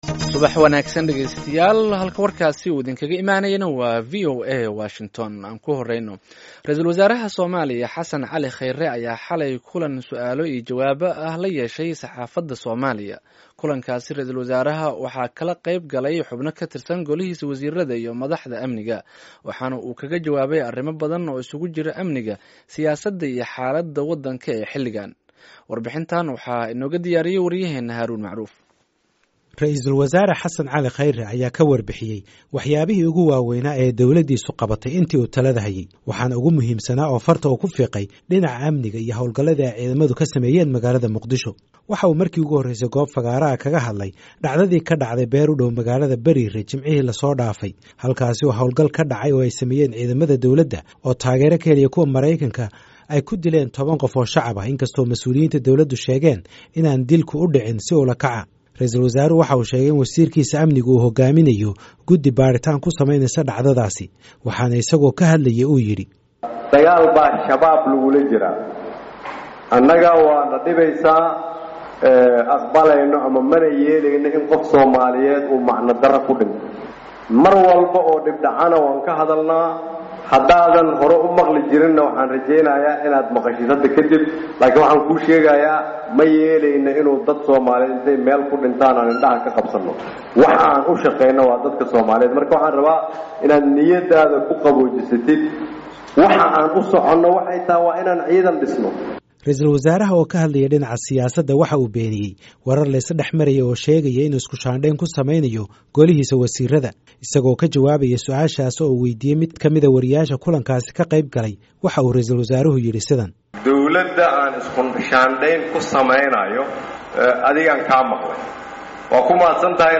Halkaan ka dhageyso warbixinta Ra'isulwasaaraha